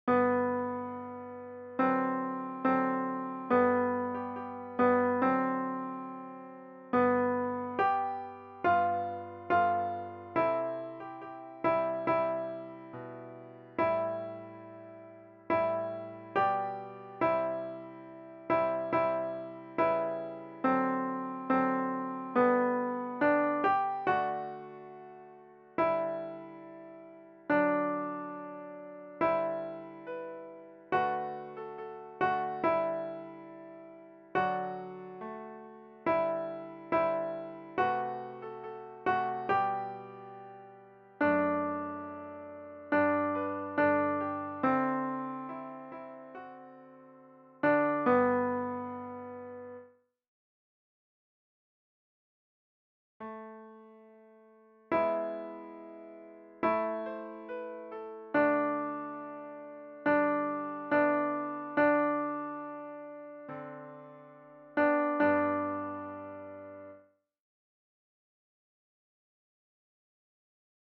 Panis-angelicus-version-avec-soliste-Alto.mp3
FKcrTRkNpKp_Panis-angelicus-version-avec-soliste-Alto.mp3